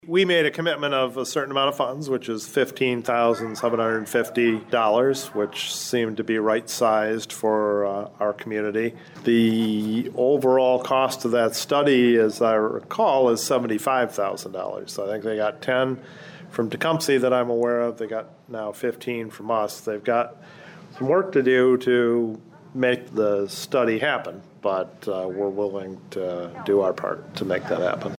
Adrian City Administrator Greg Elliott talked to WLEN News following the Commission’s regular meeting Monday night about the group’s commitment to the study…